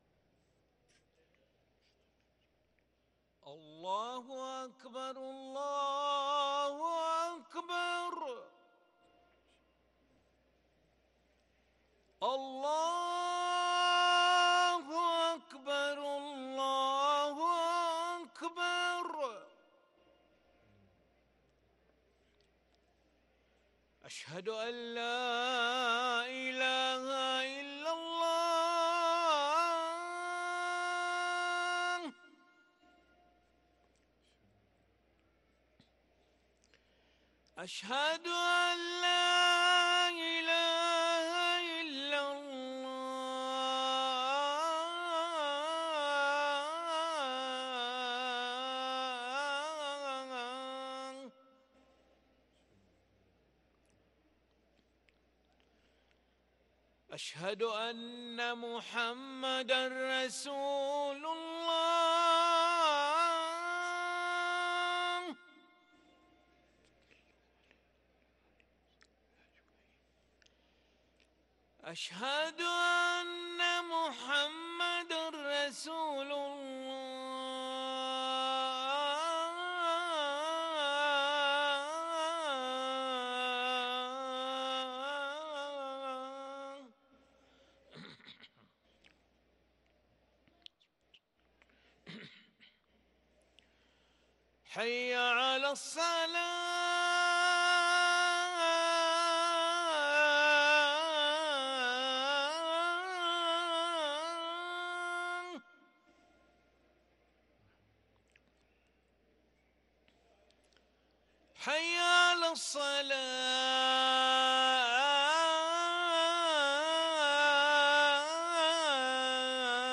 أذان العشاء للمؤذن علي ملا الأحد 26 ربيع الآخر 1444هـ > ١٤٤٤ 🕋 > ركن الأذان 🕋 > المزيد - تلاوات الحرمين